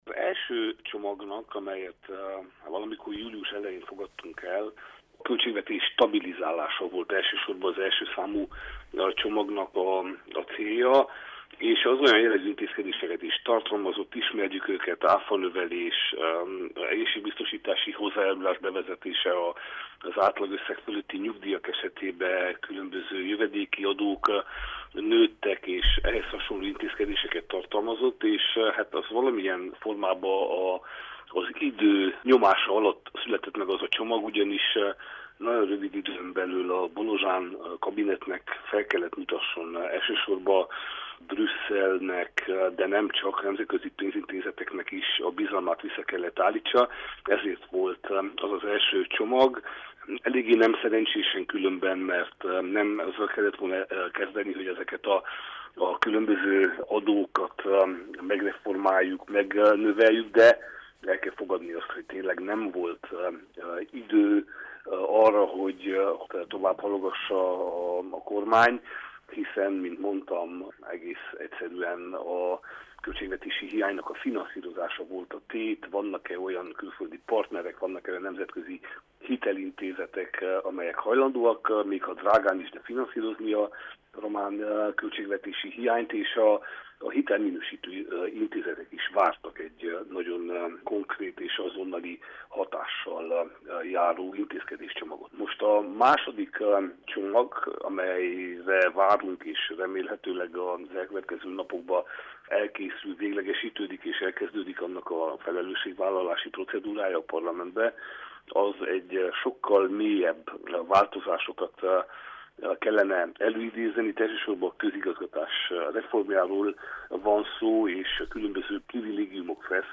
Miklós Zoltán képviselőt kérdeztük a második deficitcsökkentő csomag tartalmáról.